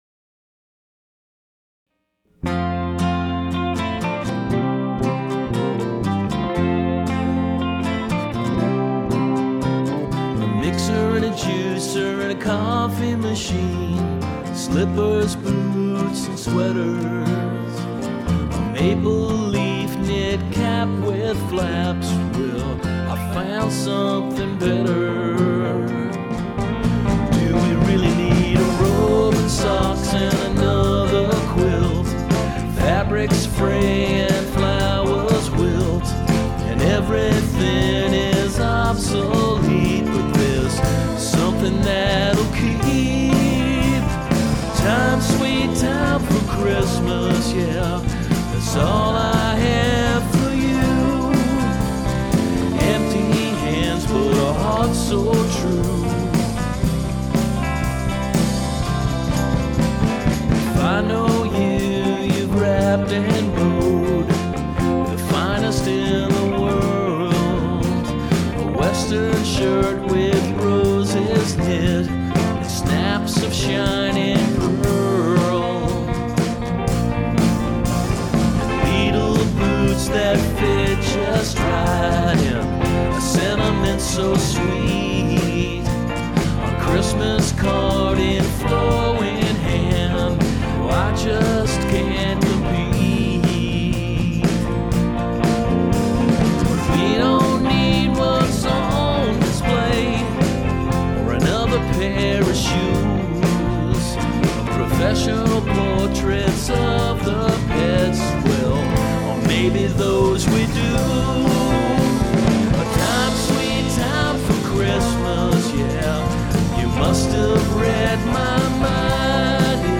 Well, here they are in all their ancient, weirdly mixed and overstuffed glory.